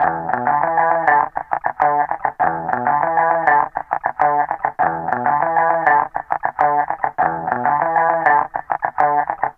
Loops guitares rythmique- 100bpm 3
Guitare rythmique 50